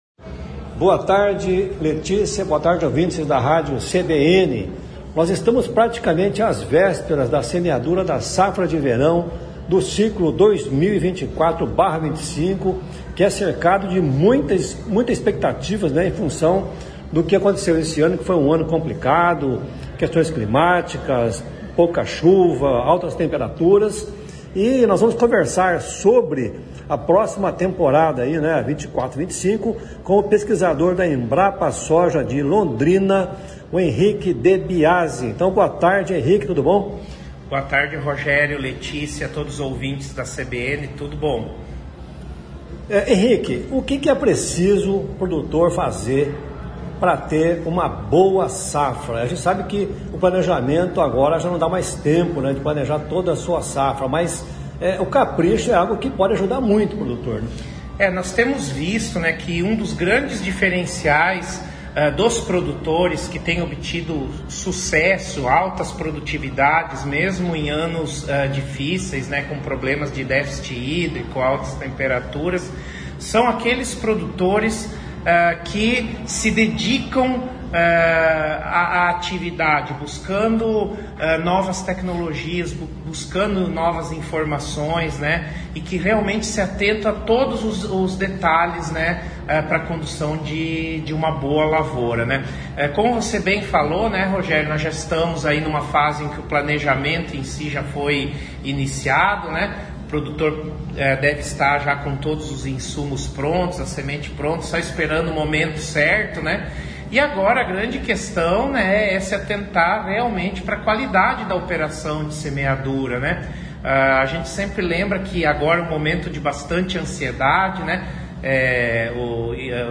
em entrevista ao CBN Rural